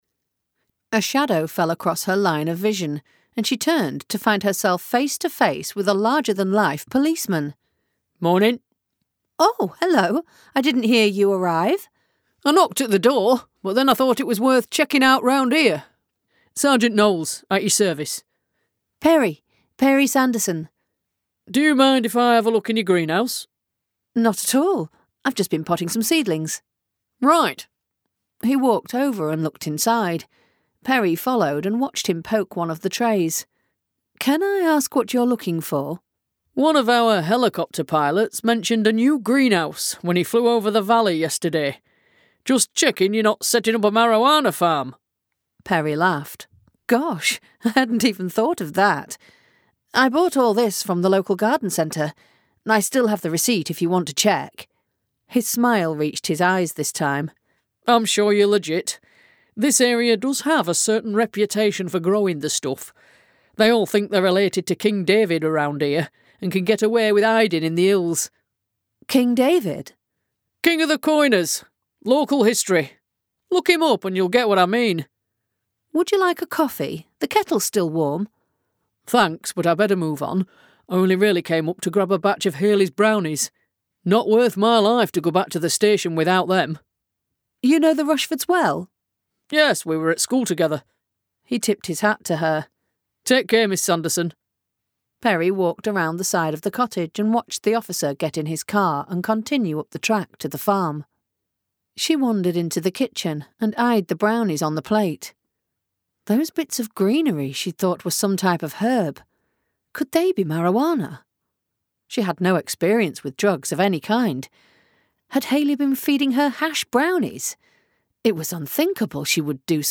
Country Living by Jen Silver [Audiobook]